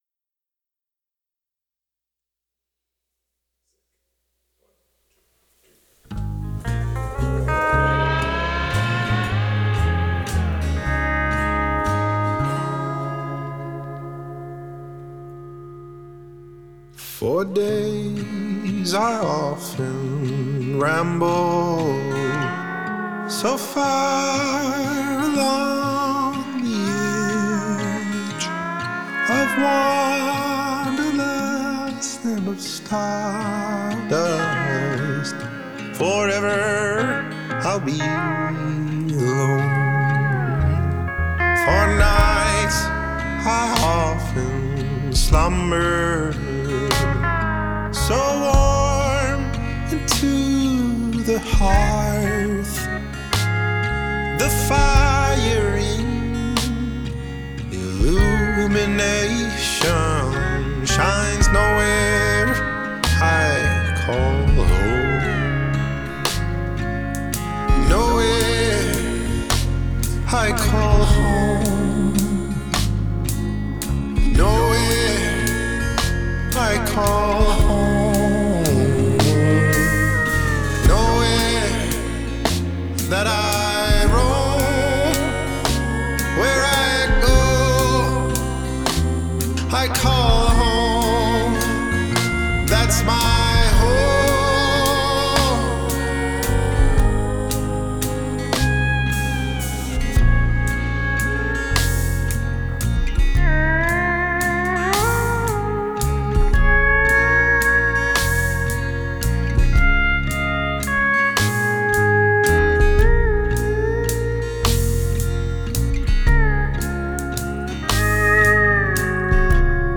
Country
Pedal Steel
Drums
Bass
Backing Vocals